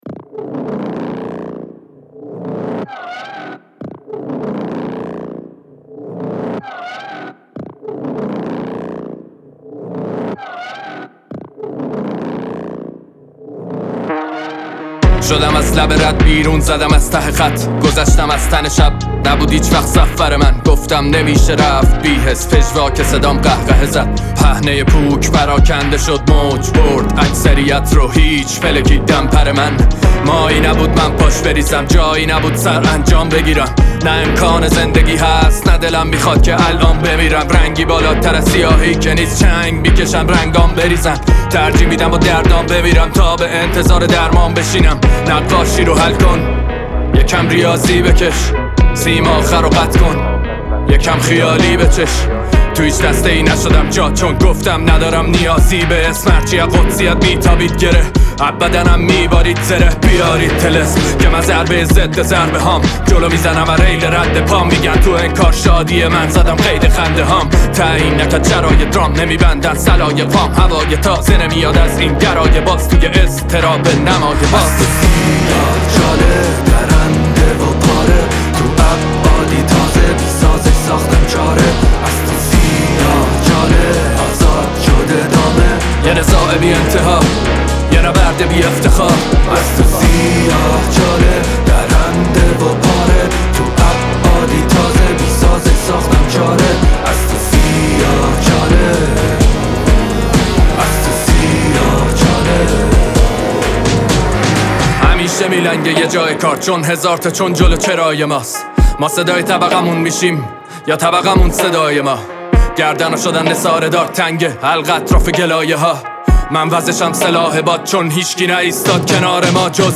رپ
اهنگ ایرانی